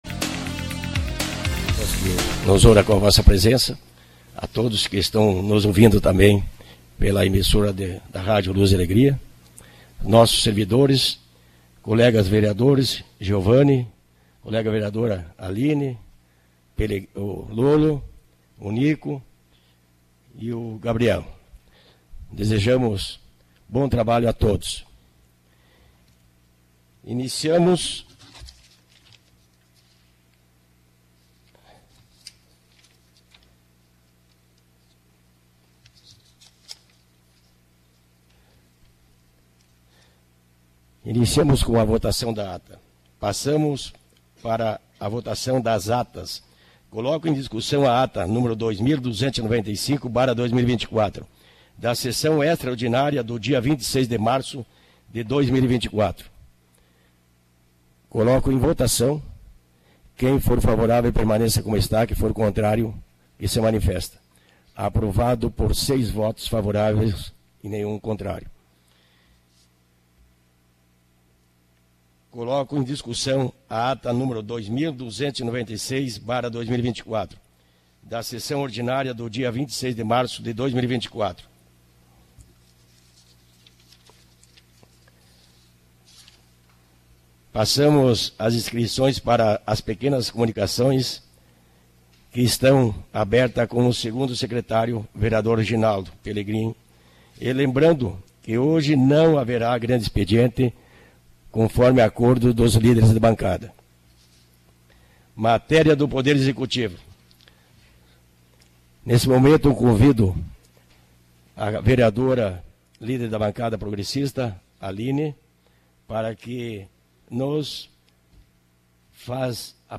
Sessão ordinária do dia 02 de abril de 2024